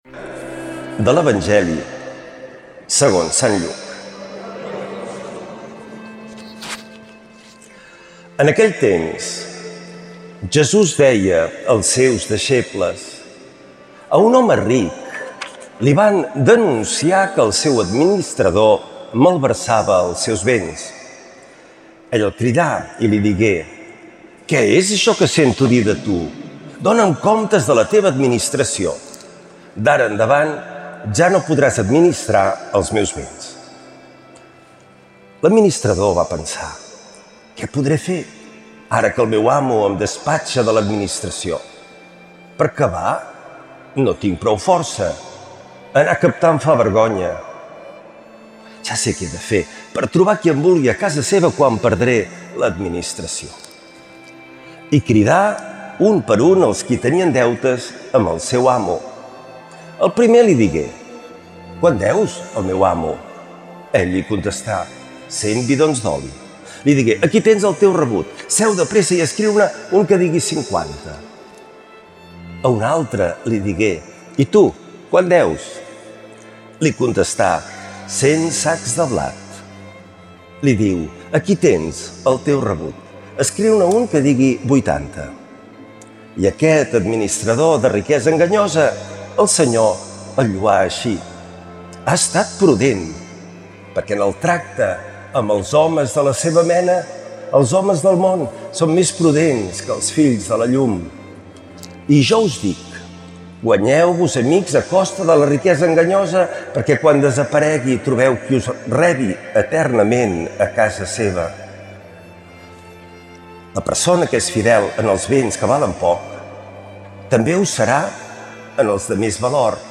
Lectura de l’evangeli segons sant Lluc